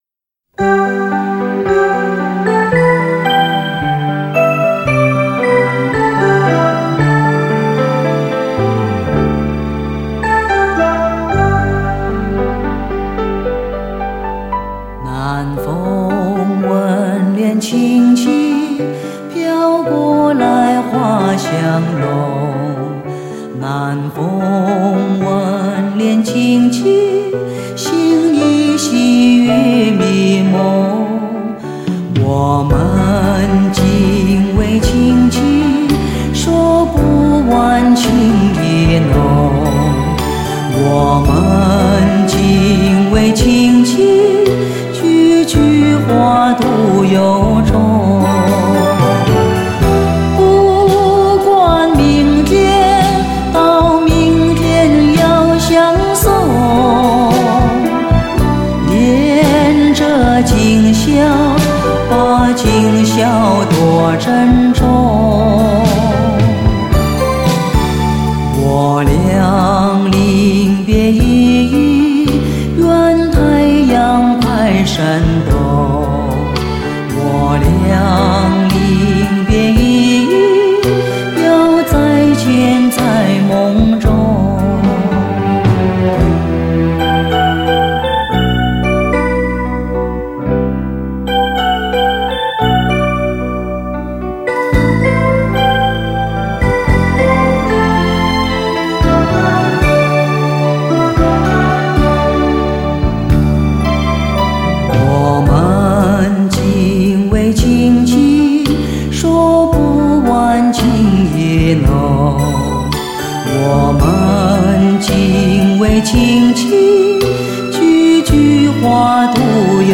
雄伟典范乐曲 必唯天作之合HI-FI典范 极致人声
高密度34bit数码录音